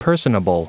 Prononciation du mot personable en anglais (fichier audio)
Prononciation du mot : personable